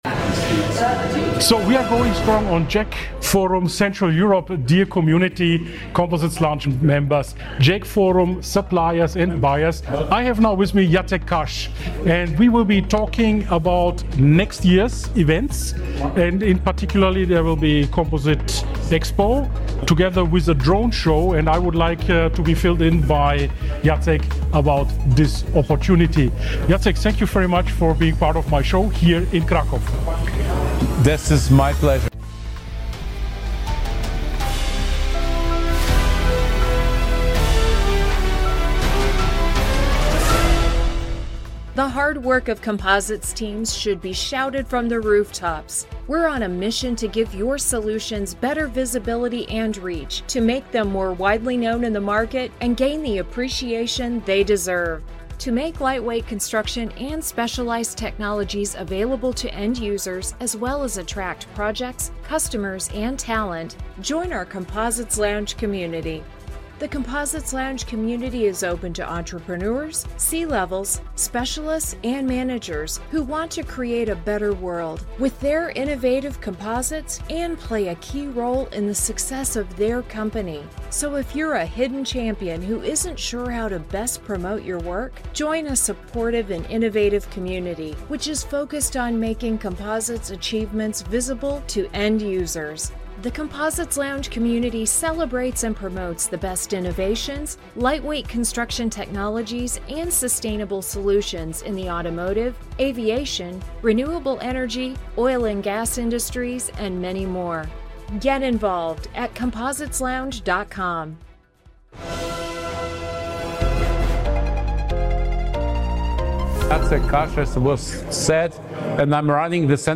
community. In a short on‑site conversation at
JEC Forum Central Europe in Kraków,